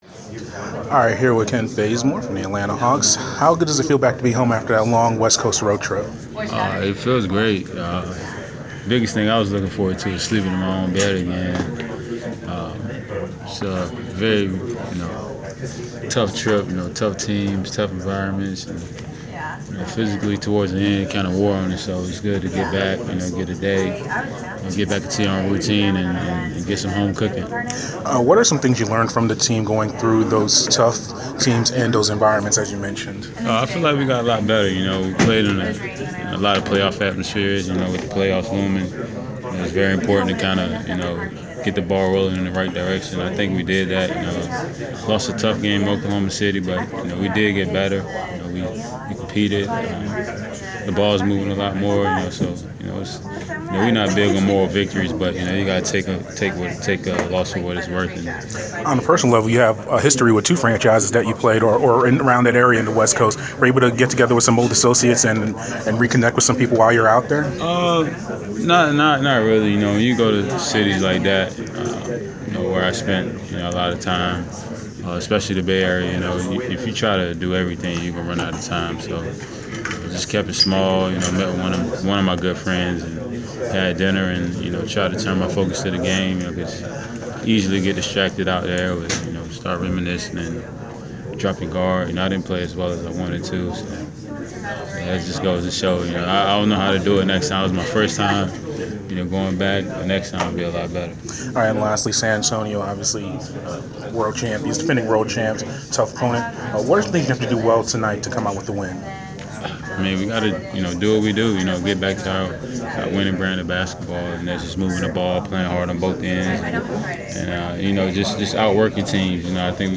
Inside the Inquirer: Pregame interview with Atlanta Hawk Kent Bazemore (3/22/15)